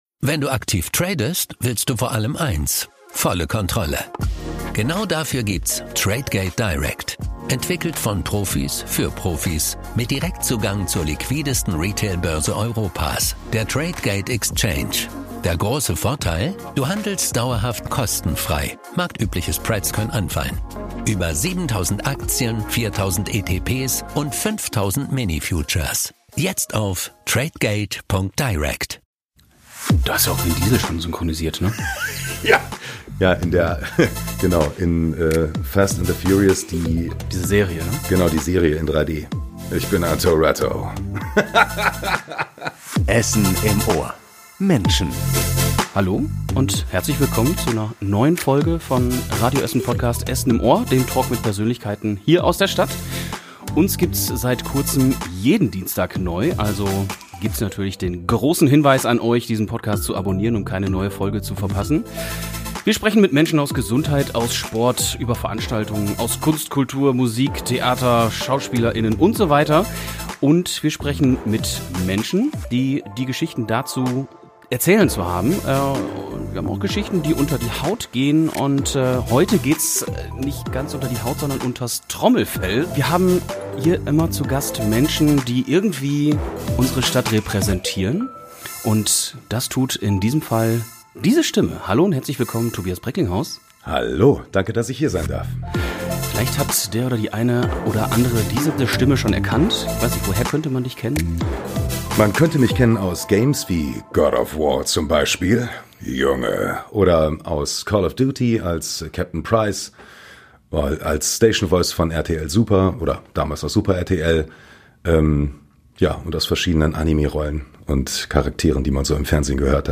Essen im Ohr - Der Talk mit Persönlichkeiten aus der Stadt Podcast